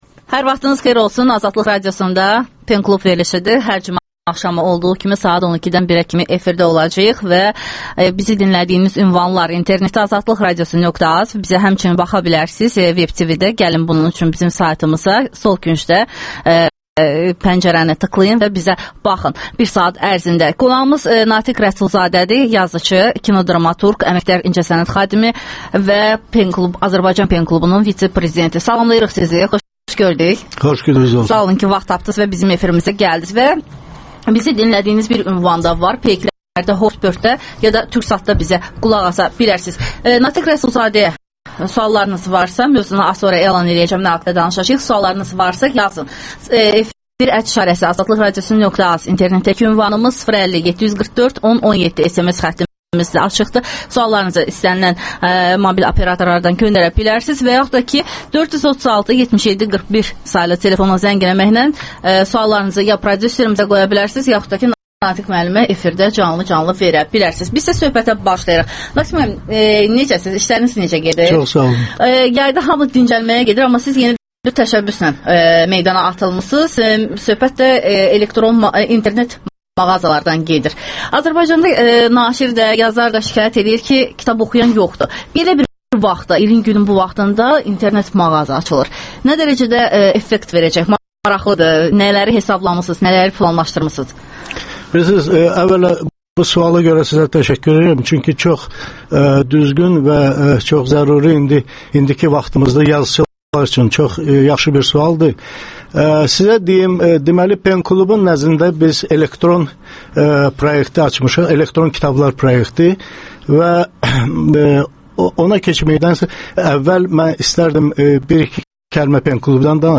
Pen club - Kitab mağazalarının bugünkü durumu qənaətbəxşdirmi? Natiq Rəsulzadə ilə söhbət
Ədəbiyyat verilişi